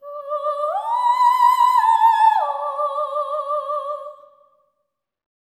OPERATIC03-L.wav